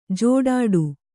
♪ jōḍāḍu